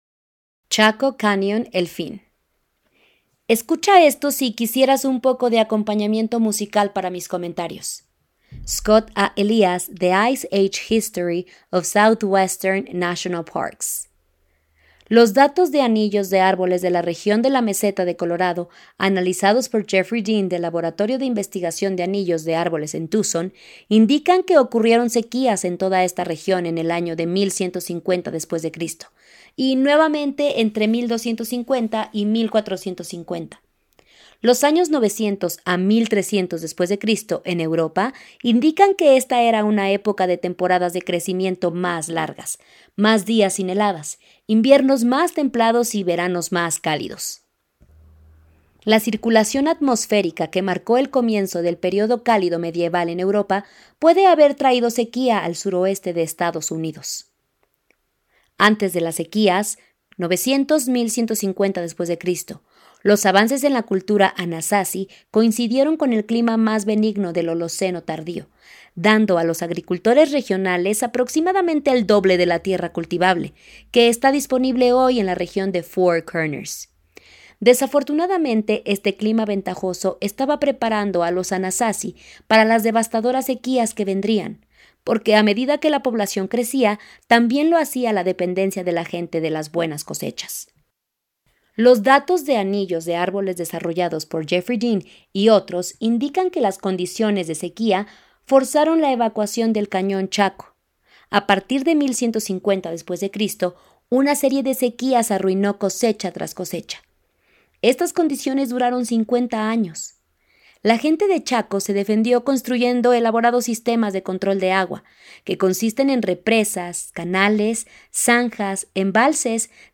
Listen to this if you'd care for some musical accompaniment to my remarks.